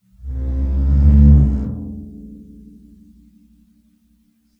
Percussion
bassdrum_rub1_v1.wav